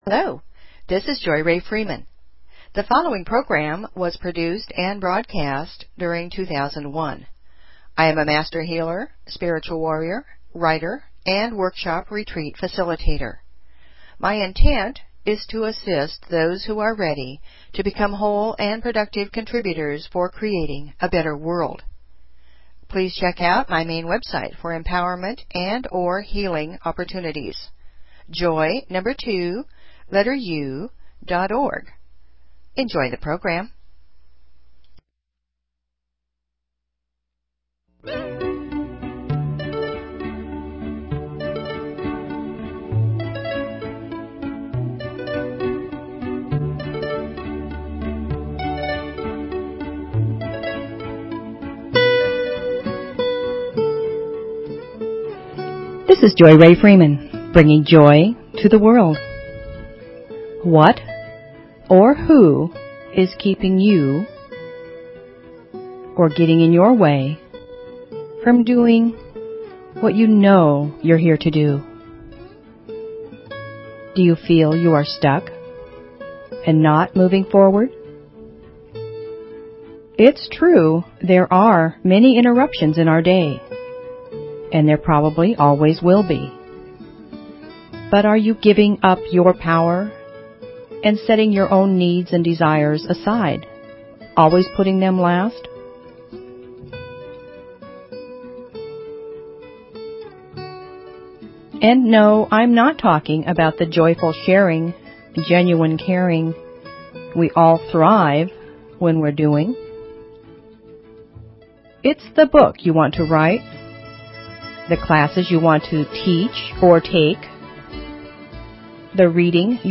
Talk Show Episode, Audio Podcast, Joy_To_The_World and Courtesy of BBS Radio on , show guests , about , categorized as
WHAT'S KEEPING YOU? (2001) Music, poetry, affirmations, stories, inspiration . . .